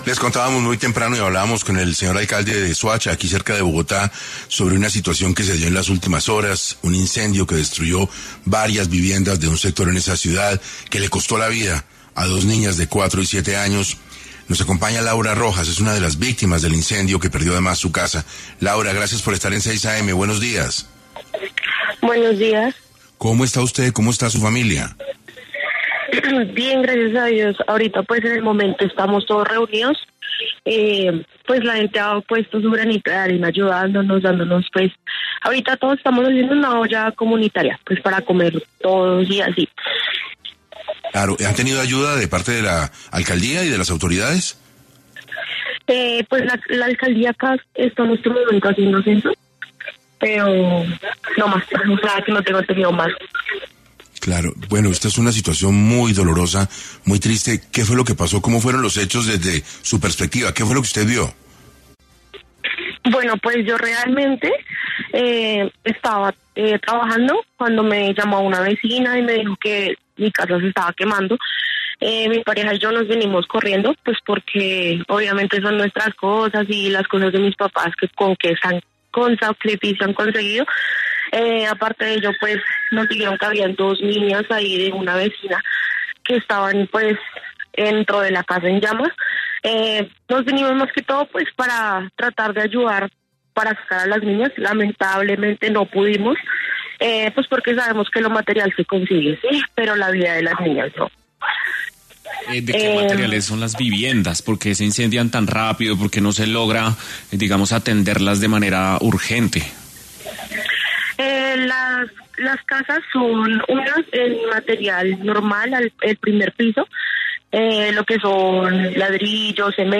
No obstante, se le escucha optimista.